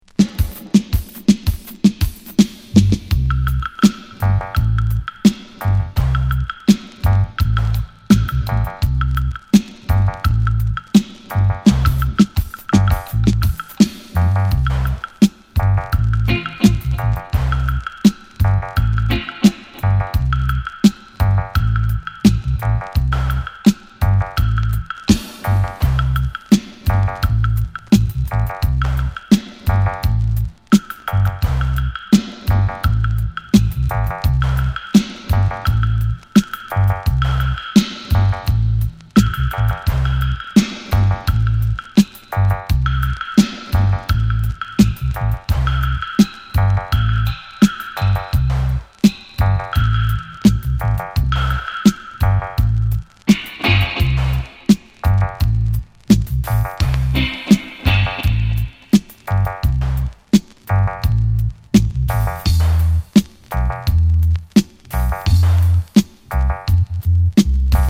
ジャマイカン・ラヴァーズとダンスホールの質感のリズムで心地良く揺られる名曲。